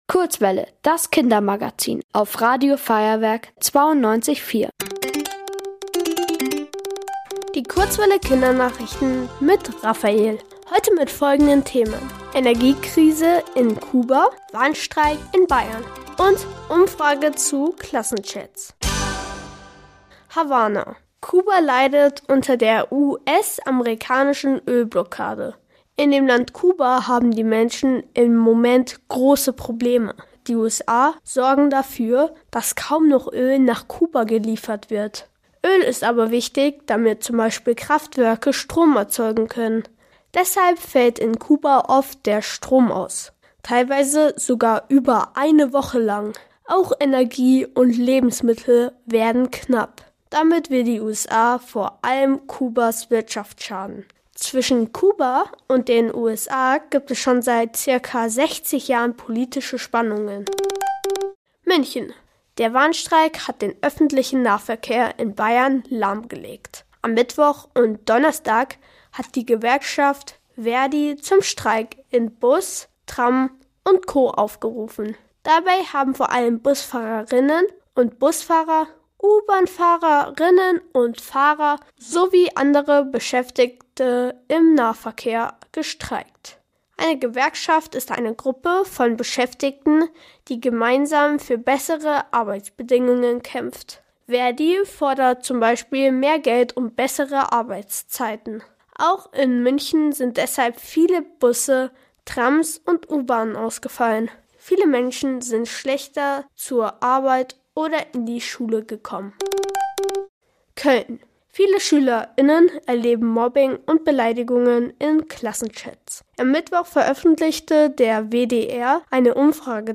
Hier gibt's die Kindernachrichten für Euch